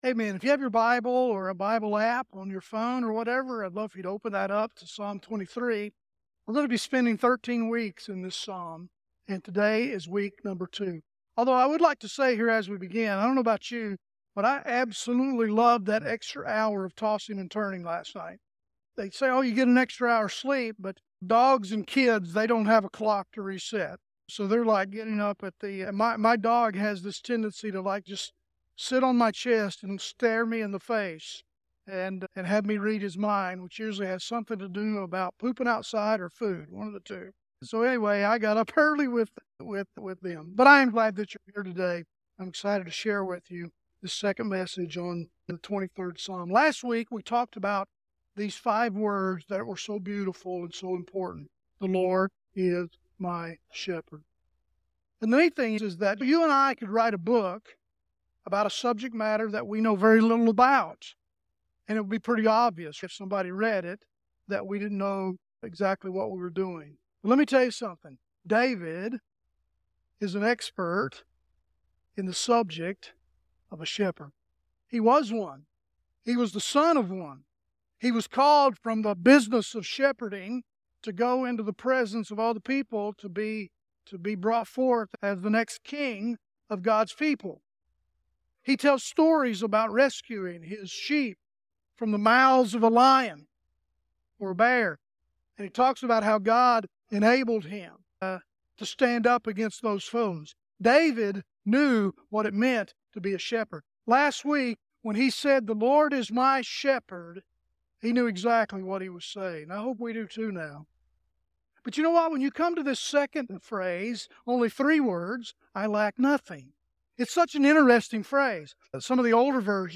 Sermon Description